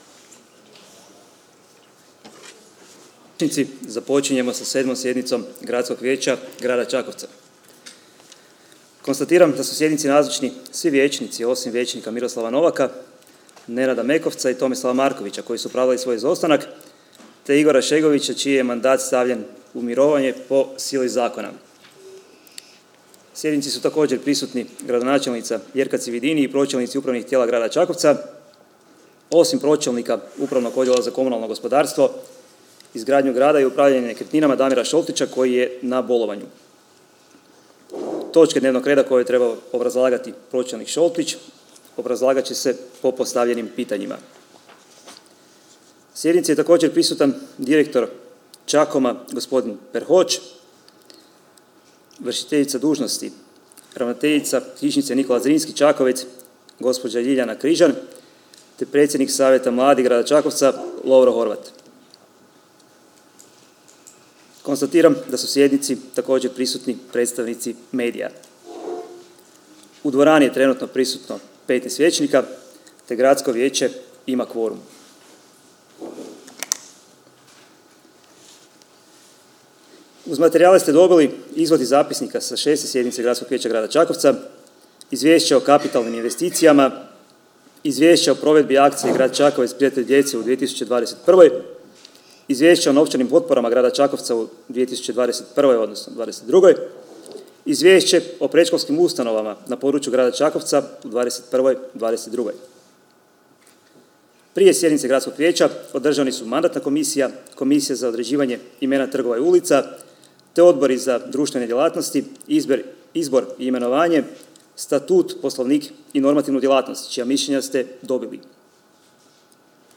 AUDIO ZAPISNIK s 7. sjednice Gradskog vijeća: